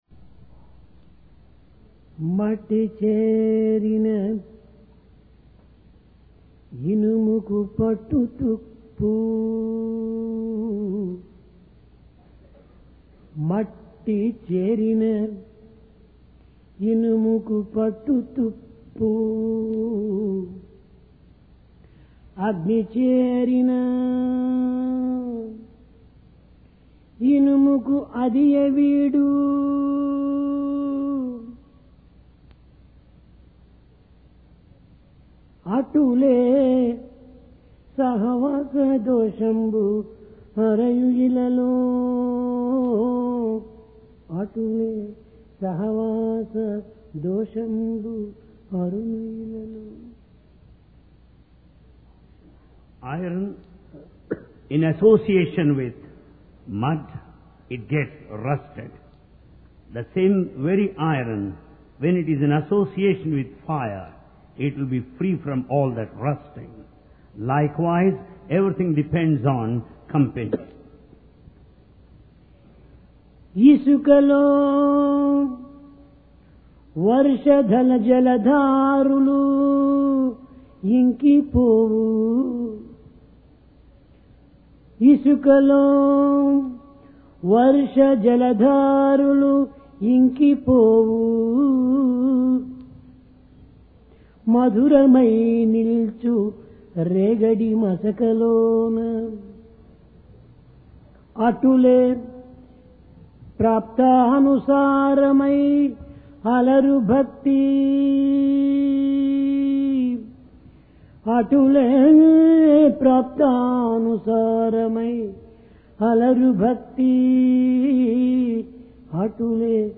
PRASHANTI VAHINI - DIVINE DISCOURSE 8 JULY, 1996